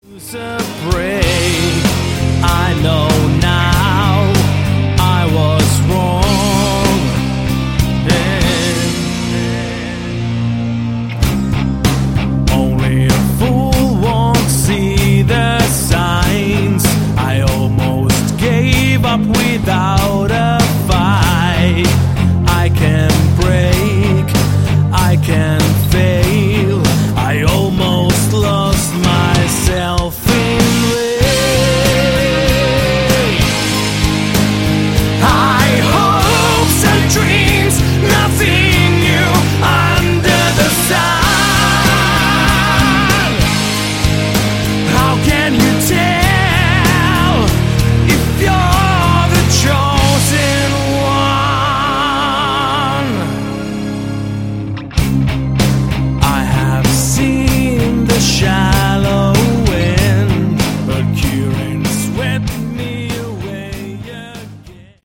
Category: Hard Rock
lead vocals
bass
guitar, vocals
drums